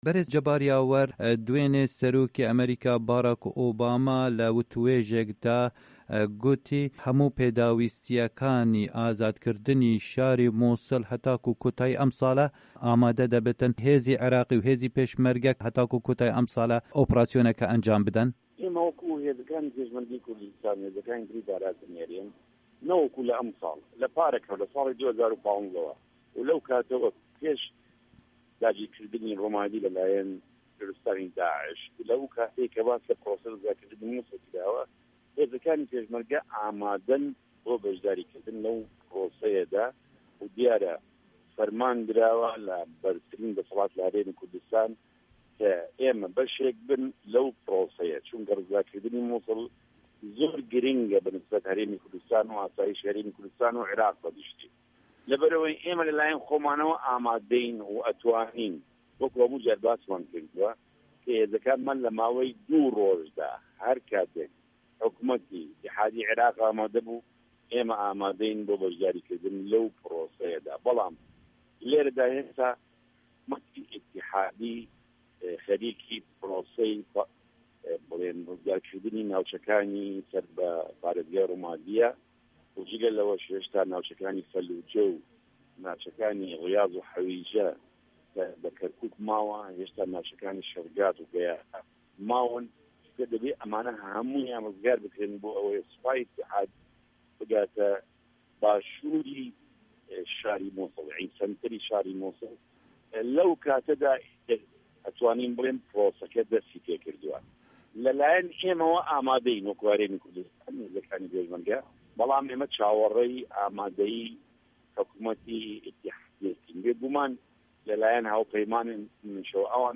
Temamiya hevpeyvîna dengê Amerîka pişka Kurdî li gel ferîq Cebar Yawer encam dayî di fayla deng de ye.